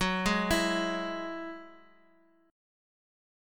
F#7sus2 Chord
Listen to F#7sus2 strummed